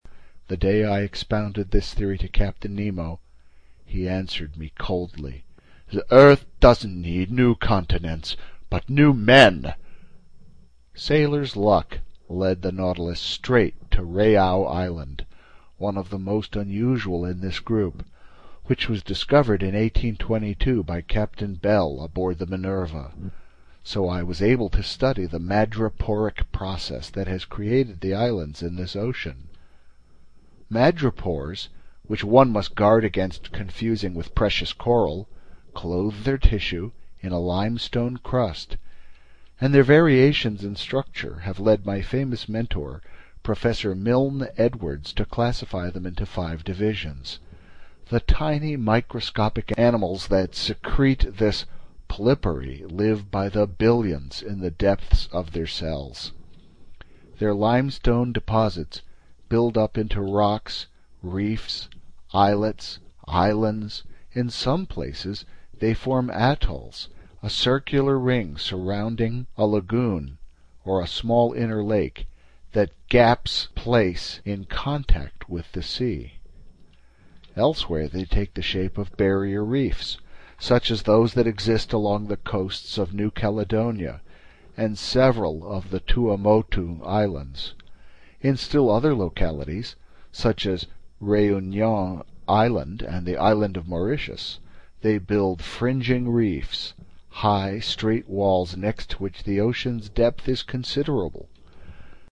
英语听书《海底两万里》第240期 第19章 万尼科罗群岛(2) 听力文件下载—在线英语听力室